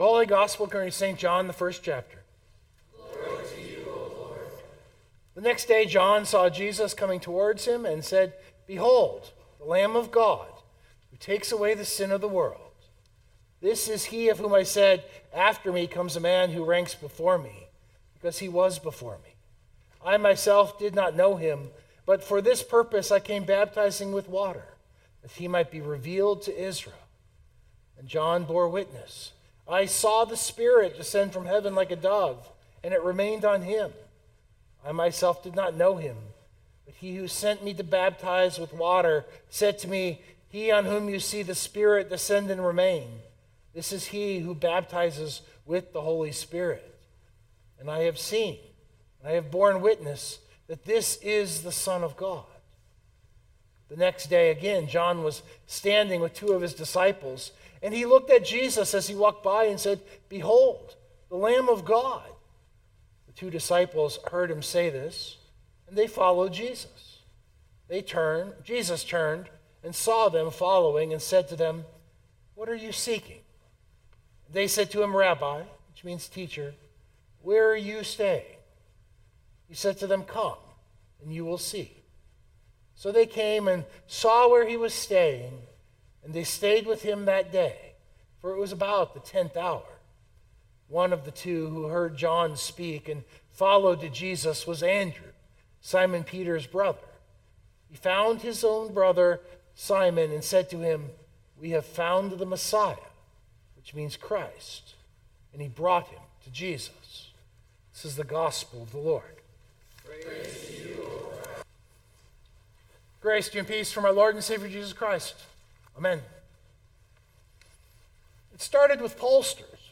011826 Sermon Download Biblical Text: John 1:29-42a The Gospel Lesson is One of the insertions from John into the lectionary.